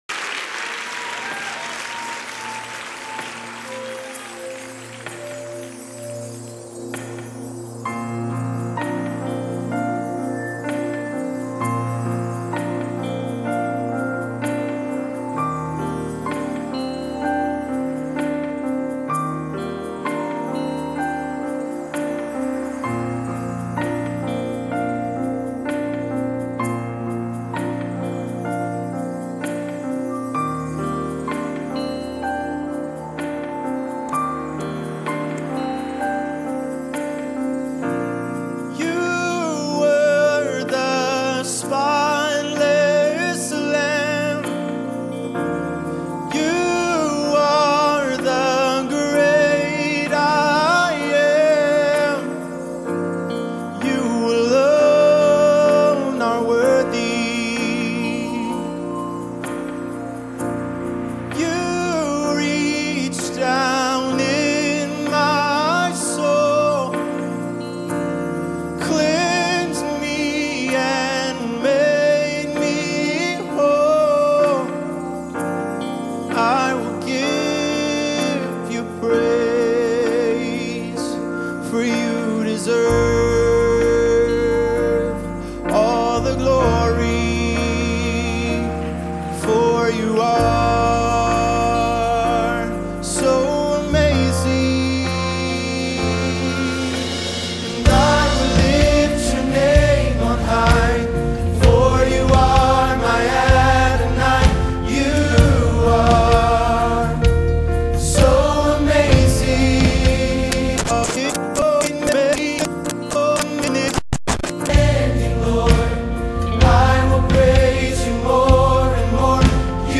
Choir, Chorale and Praise teams